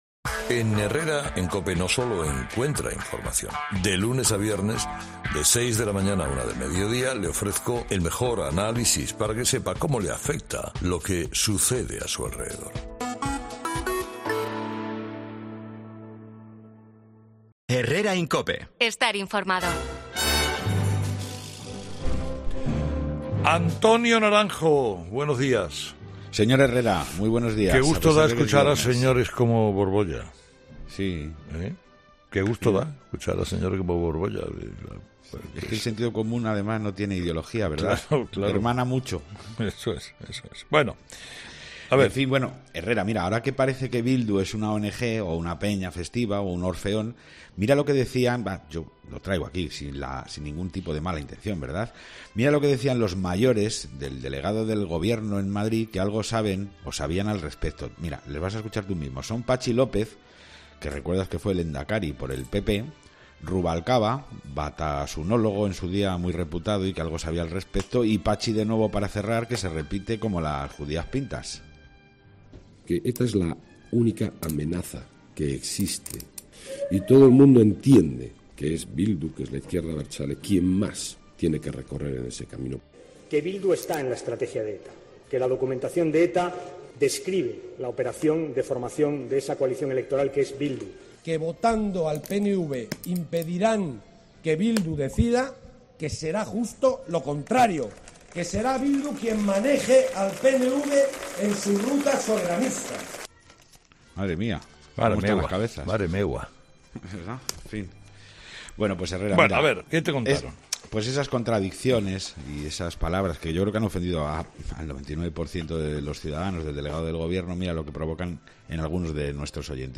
Como es habitual, Carlos Herrera escucha a los oyentes y reflexiona sobre los temas asociados a la actualidad.